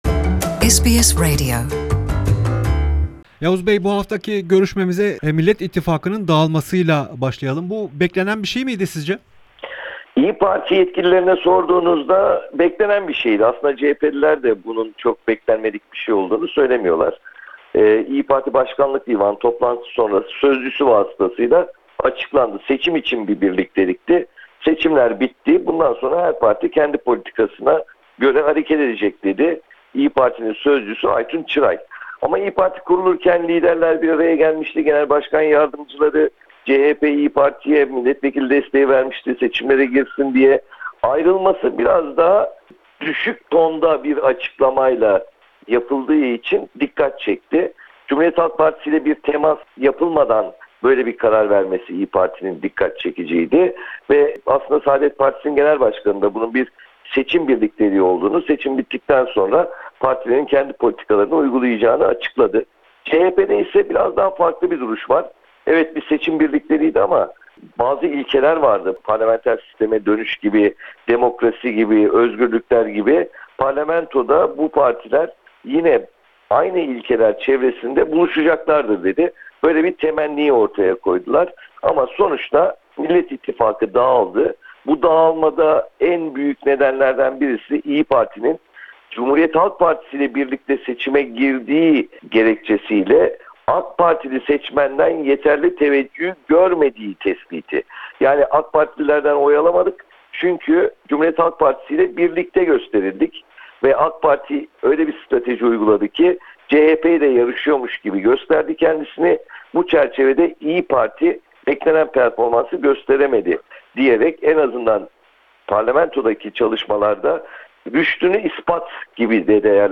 Gazeteci Yavuz Oğhan ile çocuk cinayetleri, Millet İttifakı'nın dağılması, CHP'deki istikrarsızlık ve gelecek hafta başlayacak Türkiye'nin yeni yönetim biçimini konuştuk.